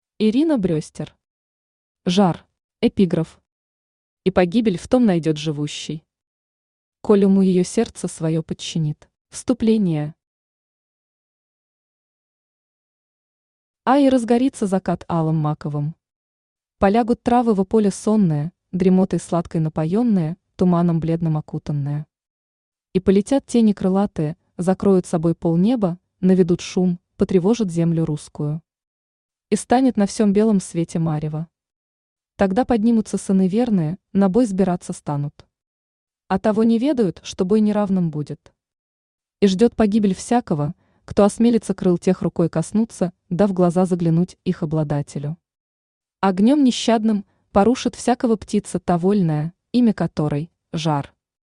Aудиокнига Жар Автор Ирина Брестер Читает аудиокнигу Авточтец ЛитРес.